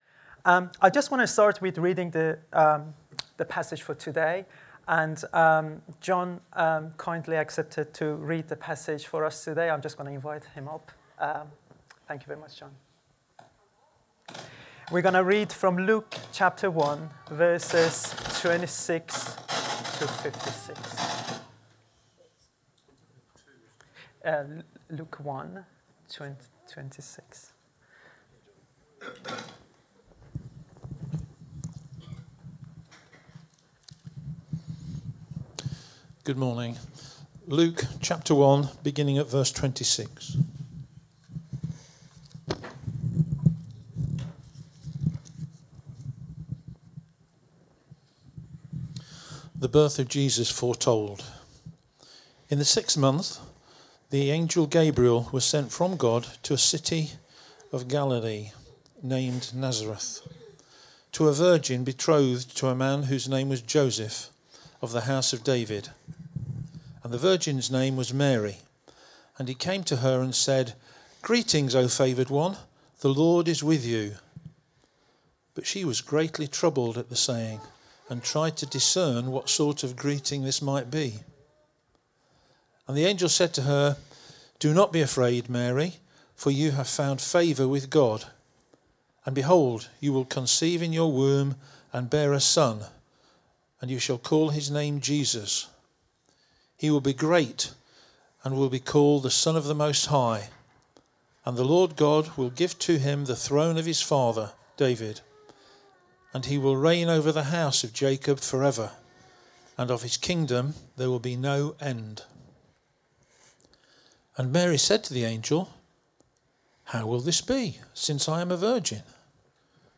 Download Advent | Sermons at Trinity Church